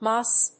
/mˈʌs(米国英語)/